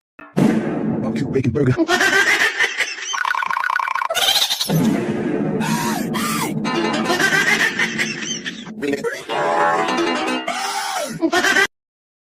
Goofy Ahh Ohio Sound Effects!! - Botão de Efeito Sonoro